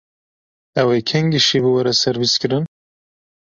Pronúnciase como (IPA) /ʃiːv/